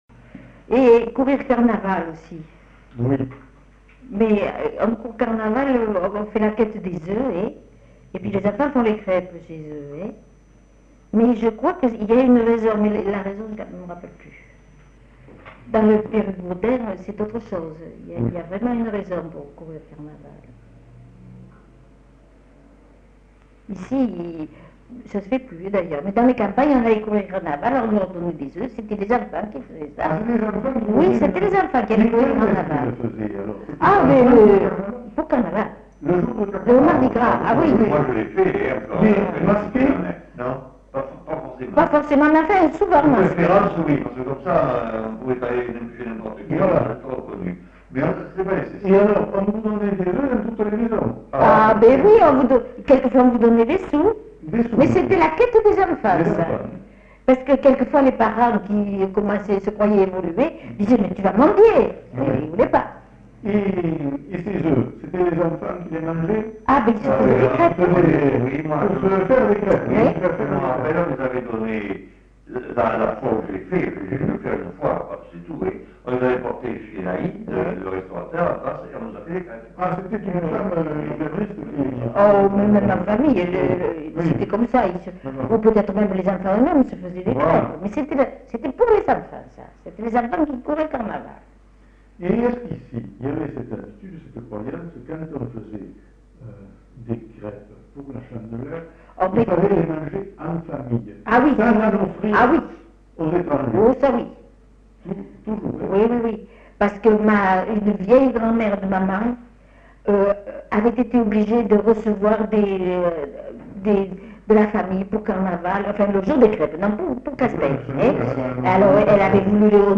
Lieu : La Réole
Genre : témoignage thématique